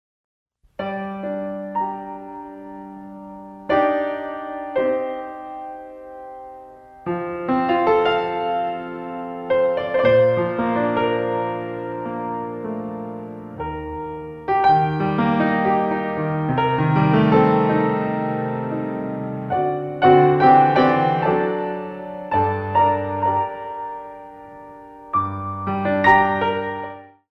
The Original PIANO Compositions
good for ballet and modern lyrical